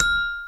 Xylophone C Major